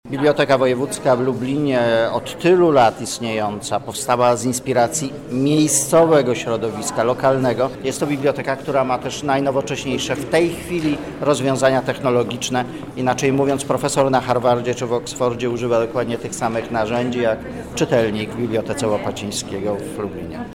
• mówi Tomasz Makowski, dyrektor Biblioteki Narodowej.